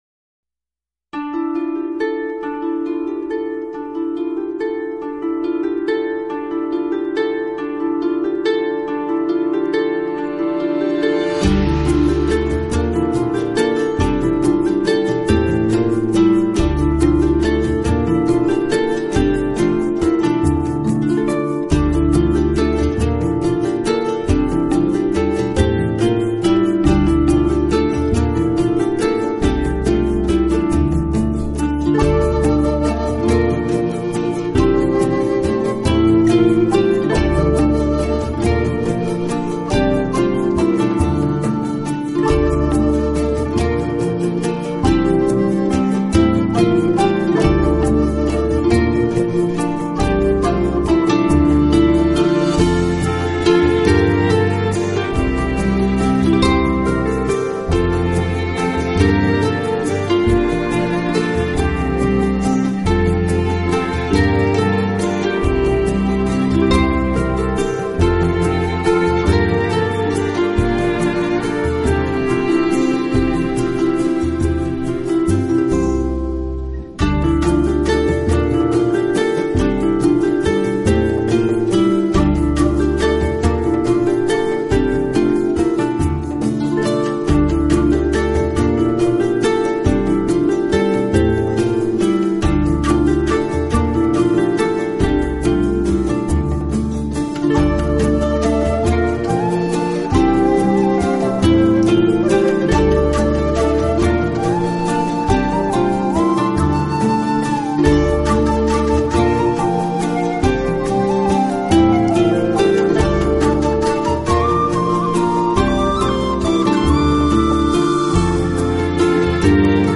【竖琴专辑】
音乐类型：New Age / Meditative / Instrumental